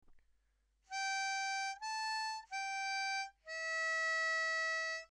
It’s a Sea Shanty sung by Sailors and Pirates.
We have chopped the tune up into small chunks to help you.